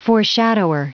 Prononciation du mot foreshadower en anglais (fichier audio)
Prononciation du mot : foreshadower